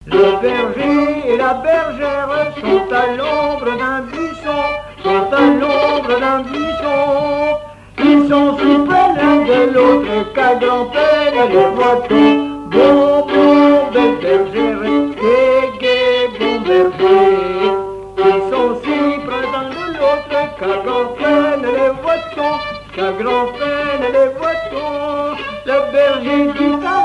Mémoires et Patrimoines vivants - RaddO est une base de données d'archives iconographiques et sonores.
Fonction d'après l'analyste gestuel : à marcher
Genre laisse
Catégorie Pièce musicale inédite